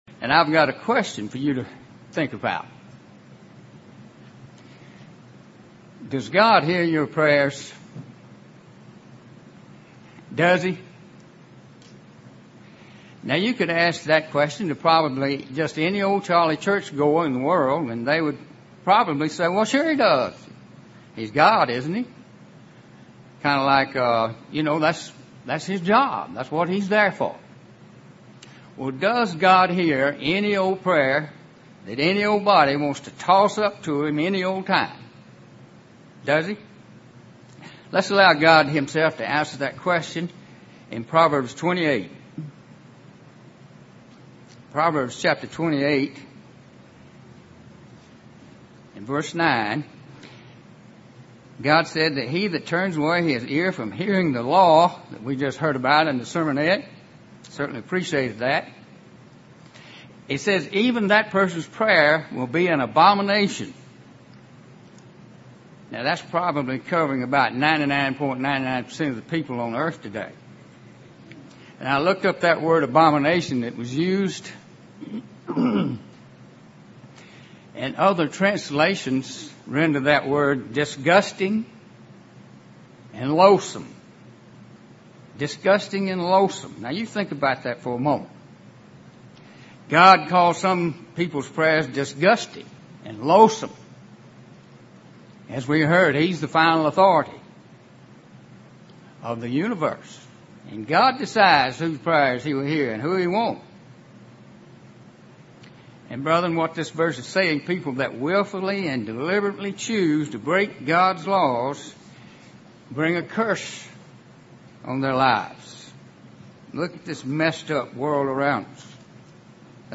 UCG Sermon Studying the bible?
Given in Columbus, GA